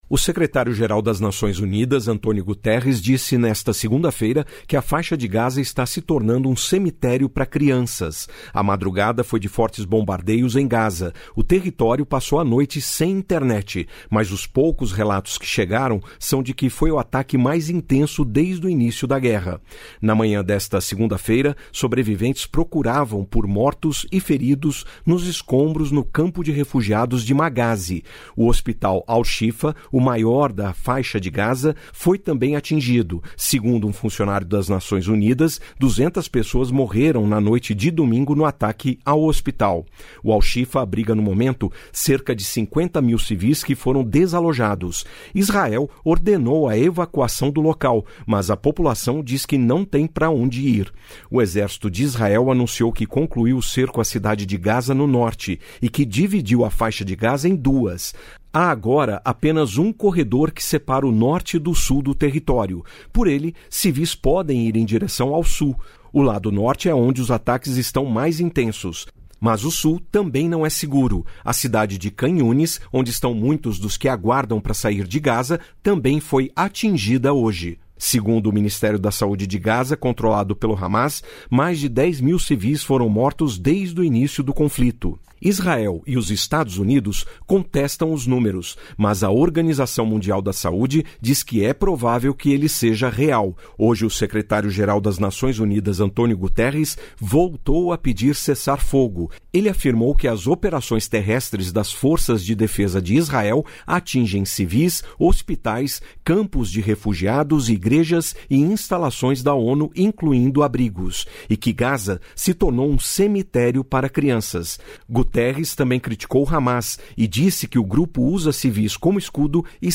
Repórter Rádio Nacional Conflito no Oriente Médio Hamas Israel Mortes segunda-feira